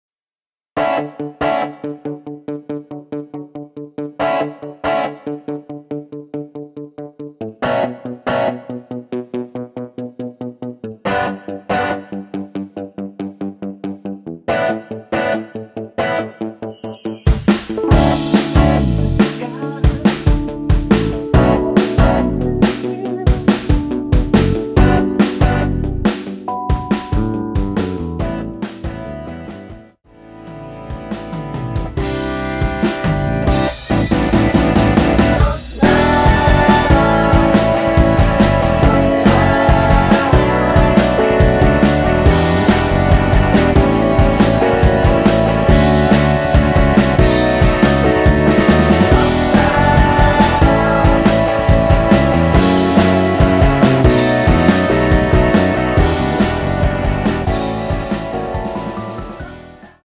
코러스 포함된 MR 입니다.(미리듣기 참조)
앞부분30초, 뒷부분30초씩 편집해서 올려 드리고 있습니다.